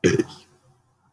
hey burp 4
burping cartoons effect funny game HEY movies musicals sound effect free sound royalty free Movies & TV